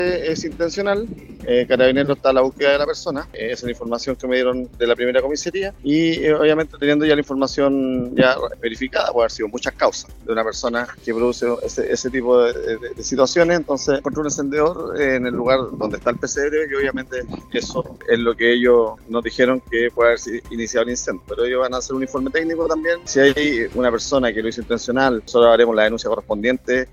alcalde-concepcion-incendio-pesebre.mp3